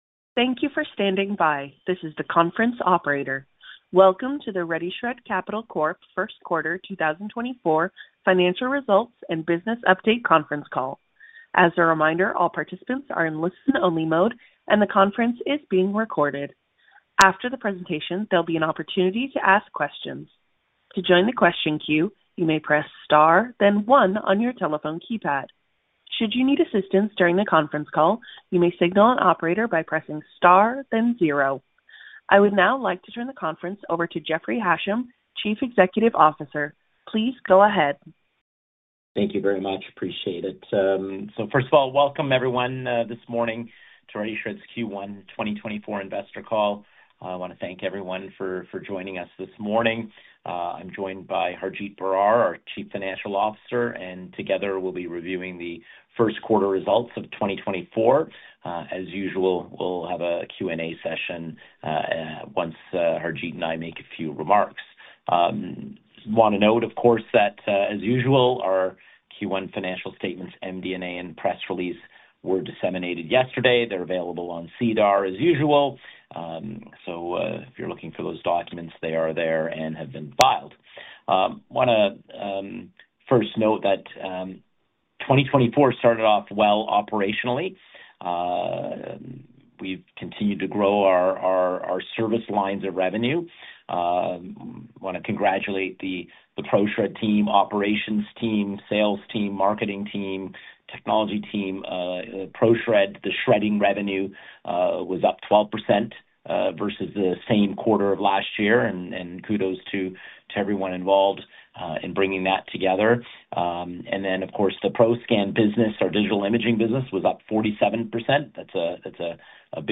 Conference Call - First Quarter 2024 | Financial Results